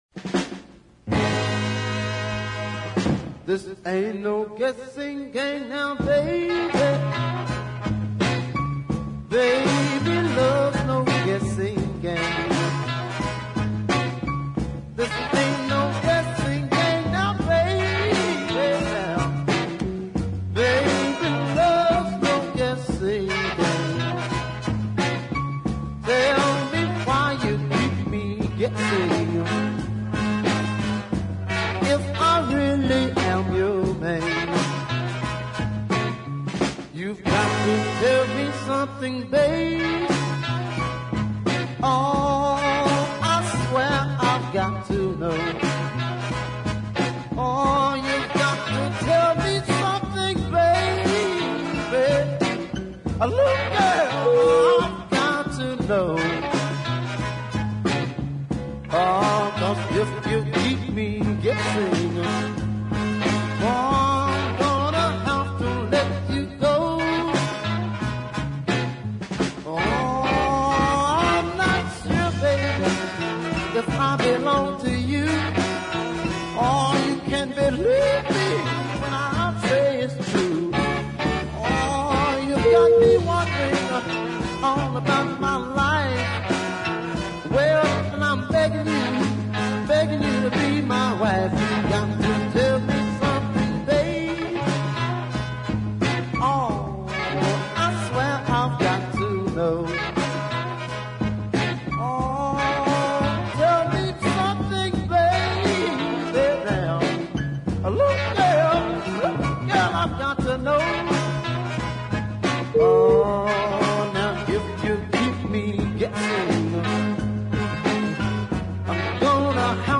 really superb minor keyed blue ballad
But the slow song has better depth and passion for me.